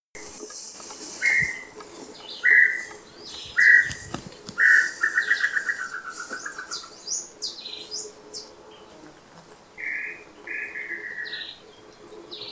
Tataupa Tinamou (Crypturellus tataupa)
Life Stage: Adult
Location or protected area: Reserva Natural Estricta Quebrada de las Higueritas
Condition: Wild
Certainty: Recorded vocal